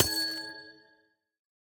Minecraft Version Minecraft Version snapshot Latest Release | Latest Snapshot snapshot / assets / minecraft / sounds / block / amethyst_cluster / break1.ogg Compare With Compare With Latest Release | Latest Snapshot